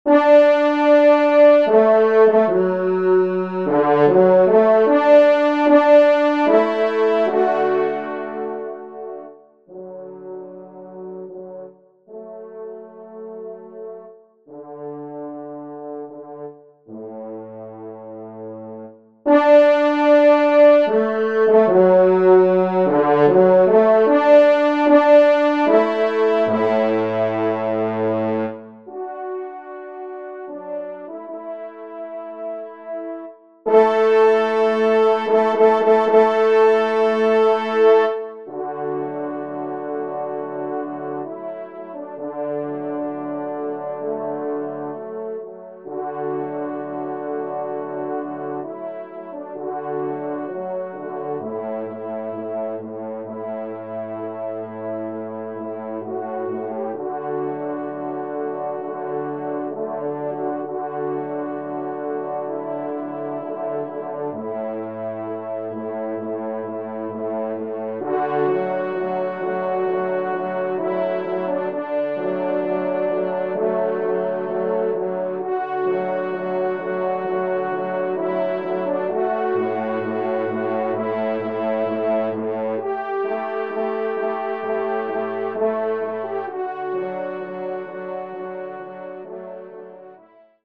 Genre :  Divertissement pour Trompes ou Cors en Ré
3e Trompe